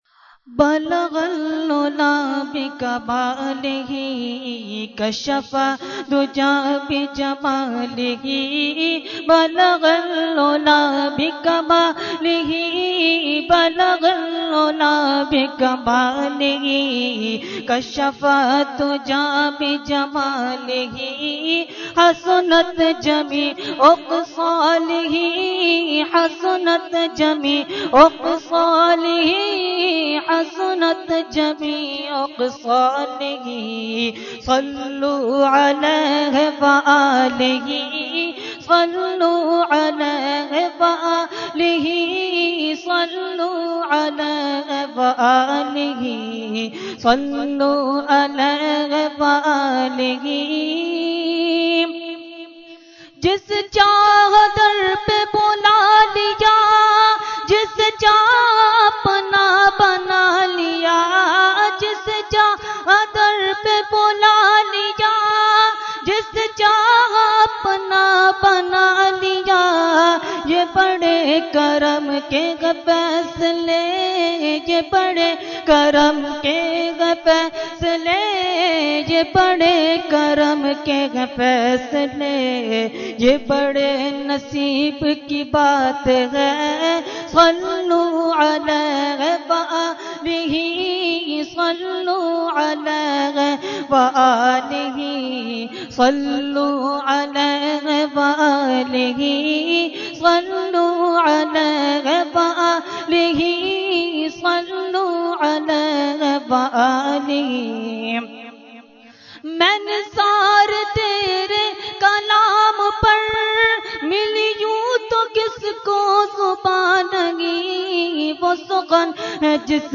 Category : Naat | Language : UrduEvent : Khatmul Quran 2014